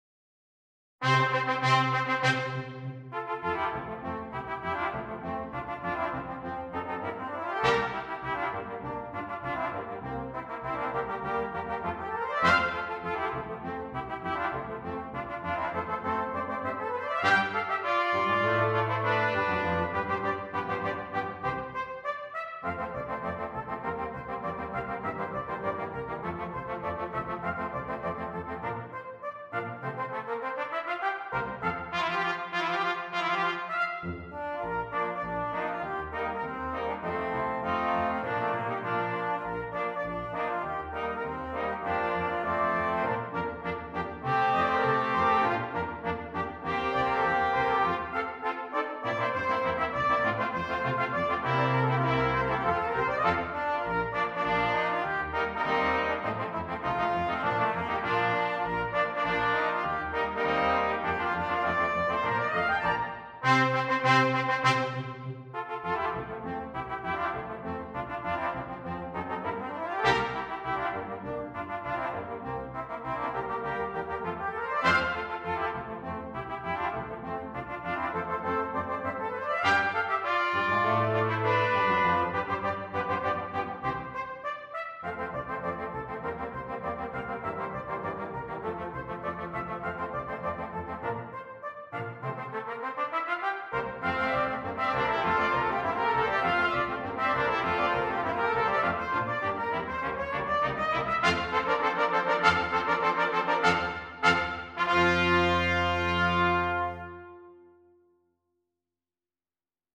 Brass Quintet
Flashy and showy that sounds harder than it is!